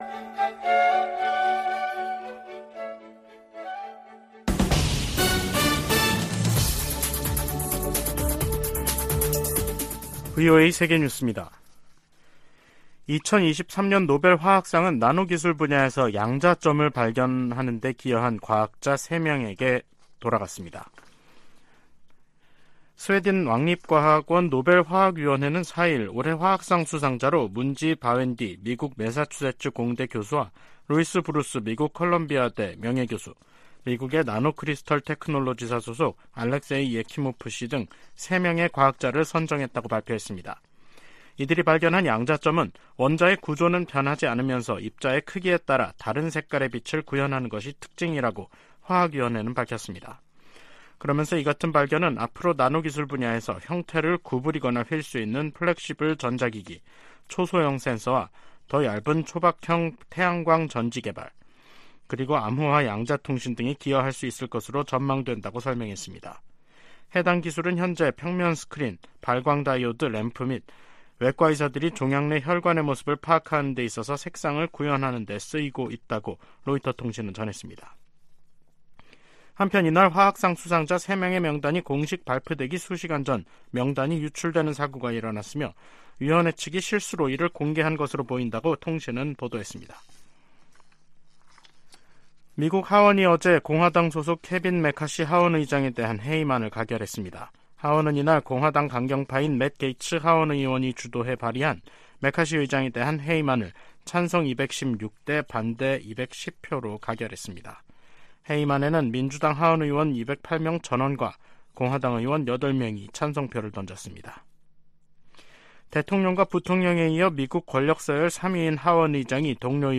세계 뉴스와 함께 미국의 모든 것을 소개하는 '생방송 여기는 워싱턴입니다', 2023년 10월 4일 저녁 방송입니다. '지구촌 오늘'에서는 유럽연합(EU)이 반도체 등 첨단기술 수출통제 방안을 검토한다는 소식 전해드리고, '아메리카 나우'에서는 케빈 매카시 하원의장이 사상 처음으로 해임된 이야기 살펴보겠습니다.